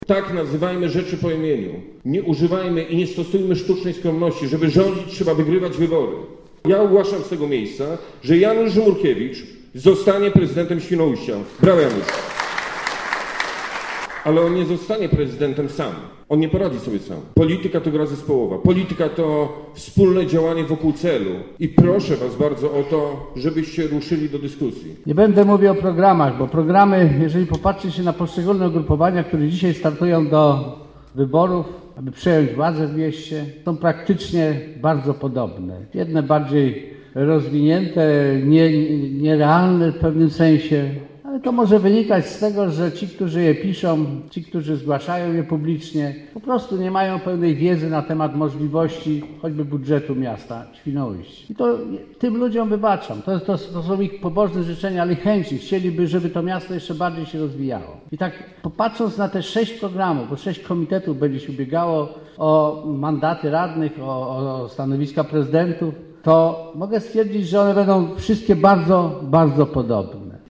¦winoujskie SLD w sali teatralnej MDK przedstawi³o w niedzielê 42 kandydatów do rady miejskiej.